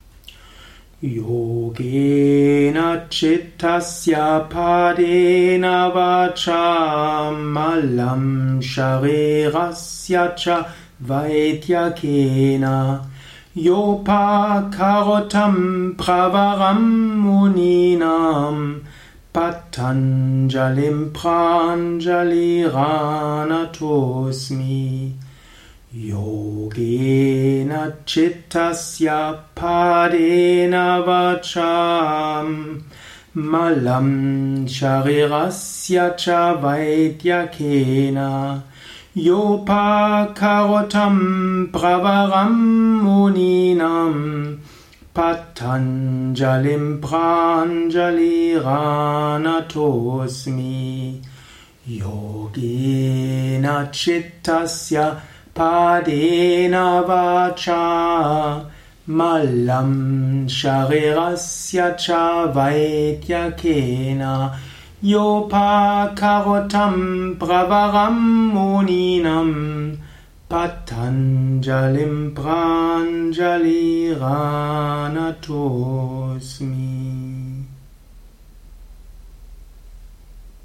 Audio mp3 Rezitationen dieses Mantras
696-Patanjali-Mantra-Yogena-Chittasya-langsam-3x.mp3